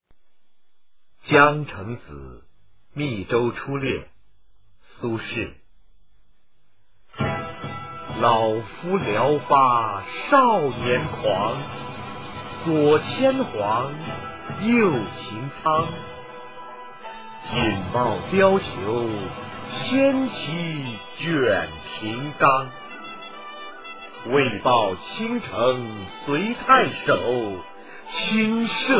九年级语文下册 12词四首《江城子·密州出猎》男声激情朗诵（音频素材）